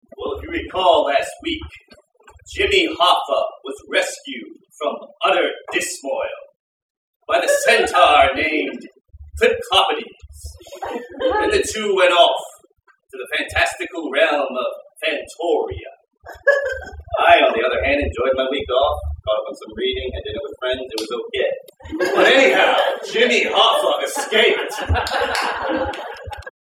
Narrator (Eps. 2-4)